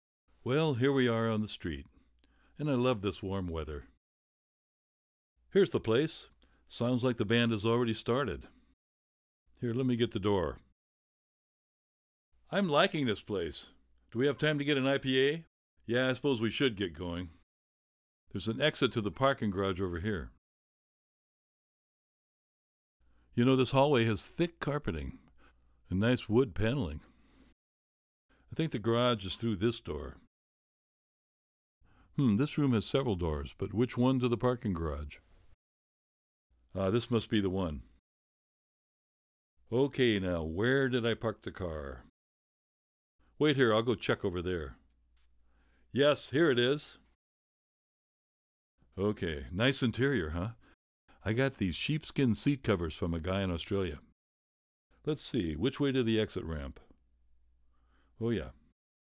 Reverb Spaces Dialog 2026.wav